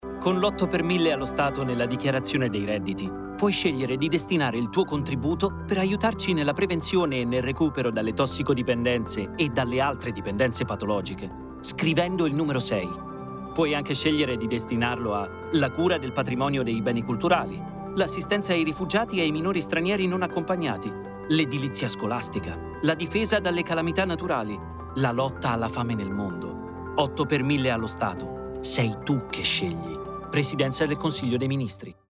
Lo spot televisivo